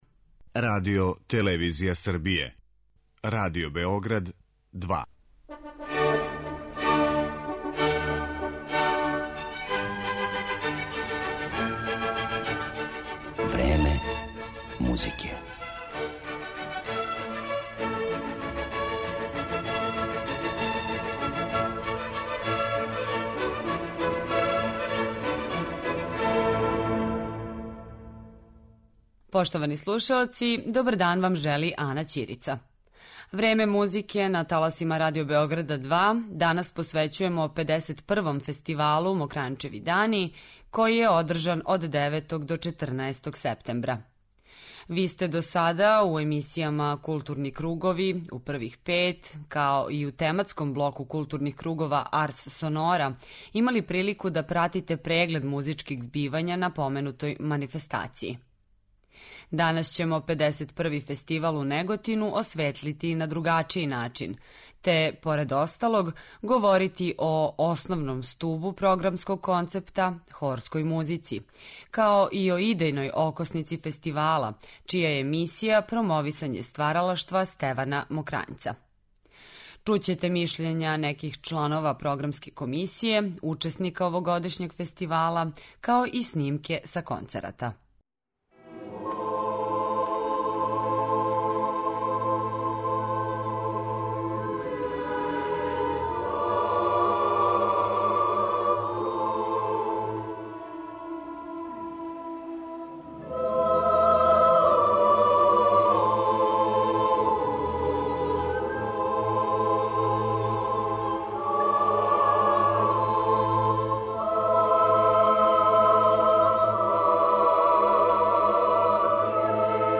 Чућете мишљења чланова програмске комисије, као и неких од учесника овогодишњих Мокрањчевих дана.